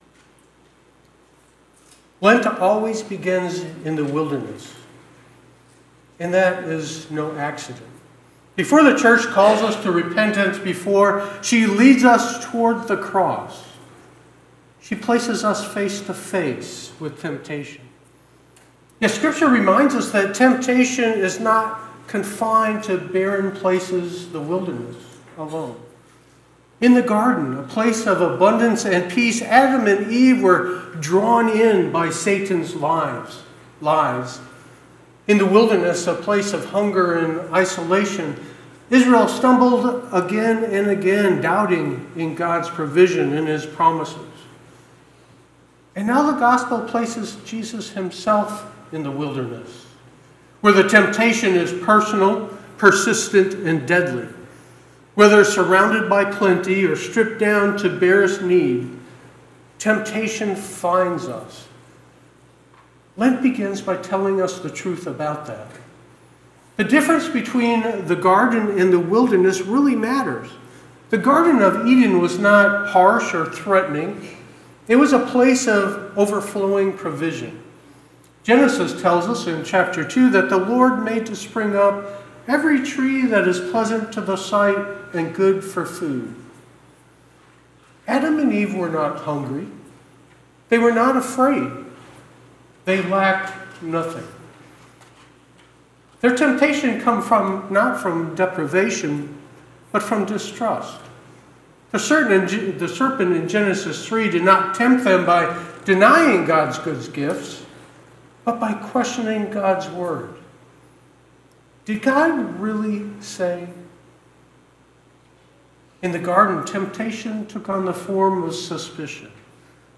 Sermons – Page 7